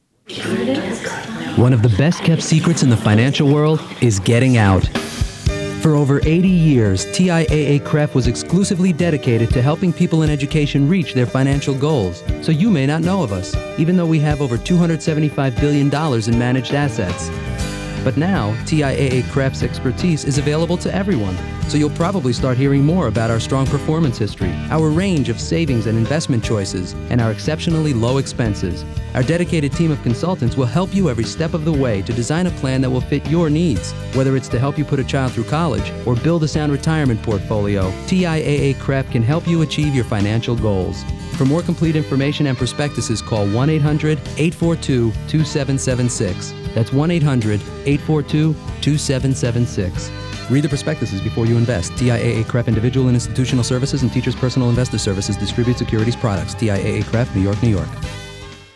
VOICE OVERS Television and Radio
RADIO